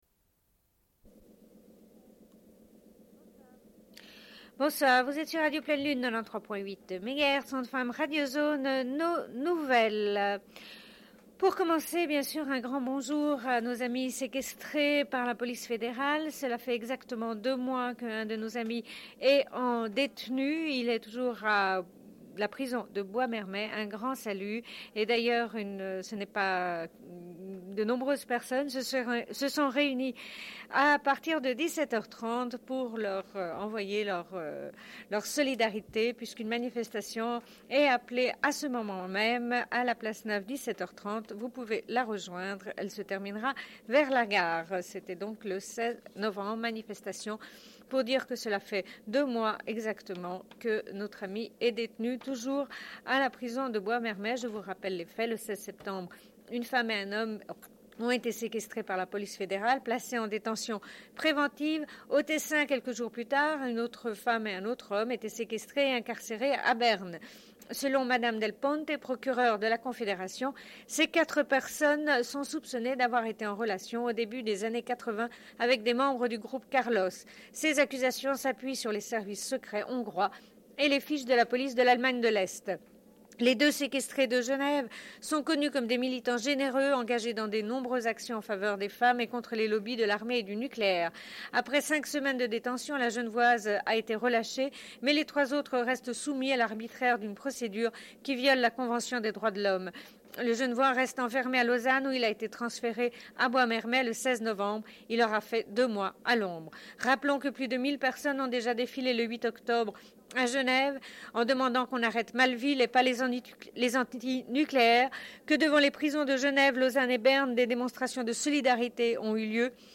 Bulletin d'information de Radio Pleine Lune du 16.11.1994 - Archives contestataires
Une cassette audio, face B